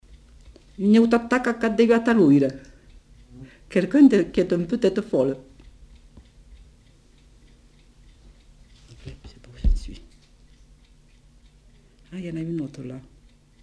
Aire culturelle : Comminges
Genre : forme brève
Type de voix : voix de femme
Production du son : récité
Classification : locution populaire